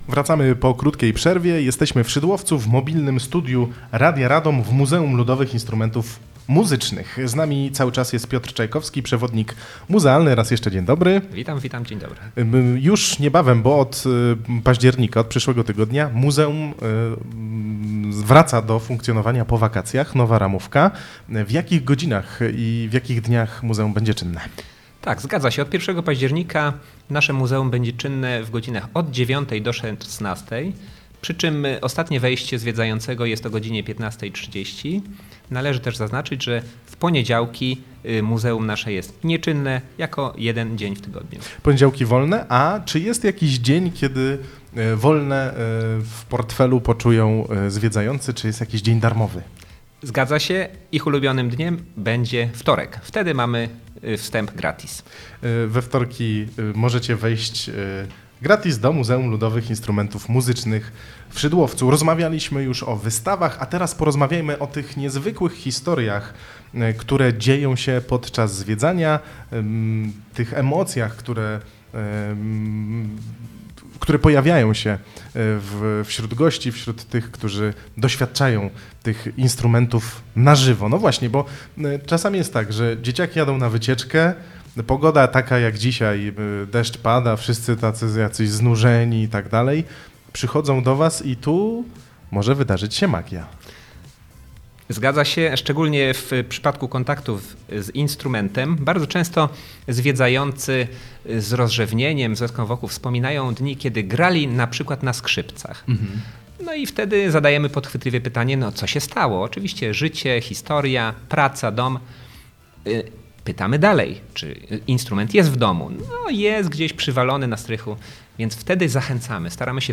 Mobilne Studio Radia Radom dzisiaj w Muzeum Ludowych Instrumentów Muzycznych w Szydłowcu
Audycja powstała przy współpracy z Samorządem Województwa Mazowieckiego